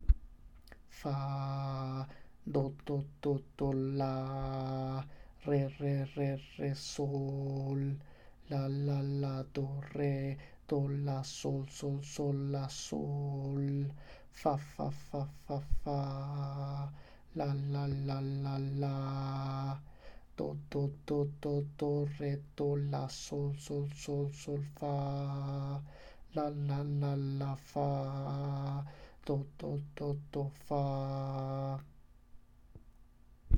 In this third exercise we will focus again repetition of a note in a fast subdivision, eighth notes, but this time we will explore intervallic leaps guided by sequences: transposition of small musical motif.
Again set up your metronome at 80 BPM and practice the exercise following the rhythm but speaking the syllables instead of singing the pitches.
That’s right, it was composed with a pentatonic scale.